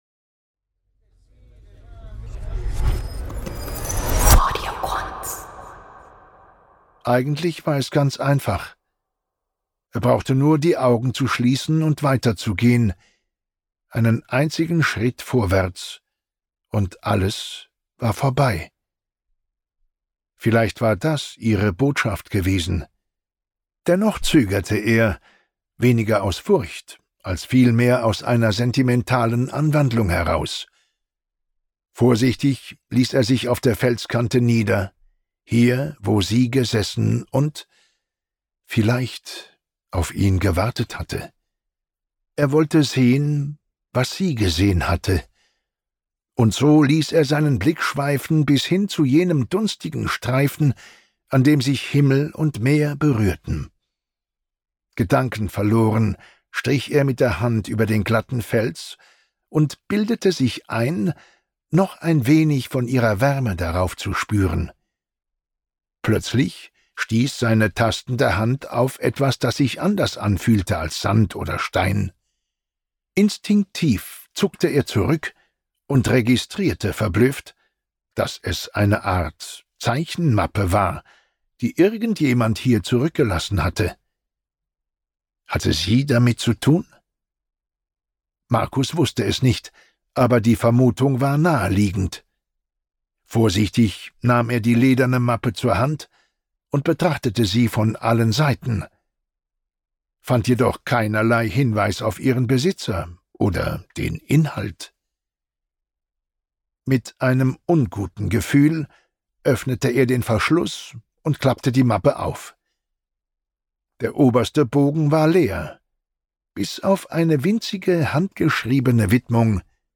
Hörbuch "Dämonenstadt" | Audio Quants